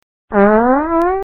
fart_01.mp3